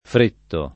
fretto [ fr % tto ]